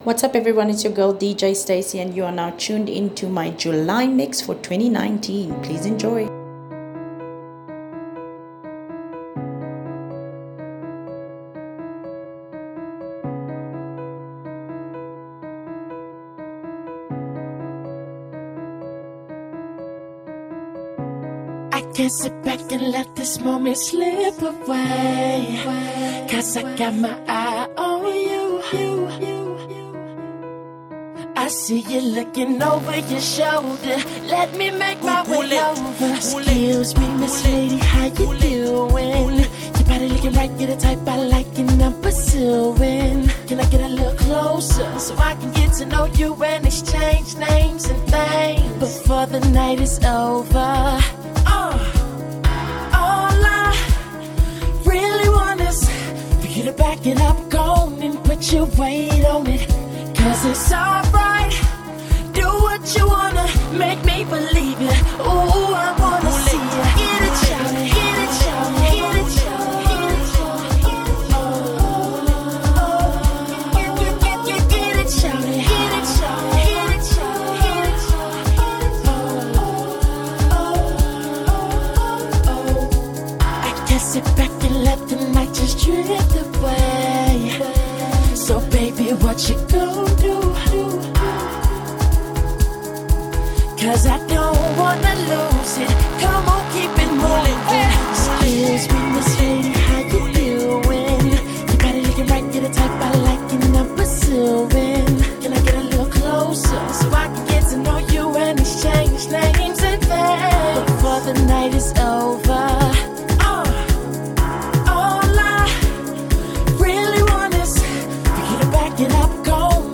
Afro House mix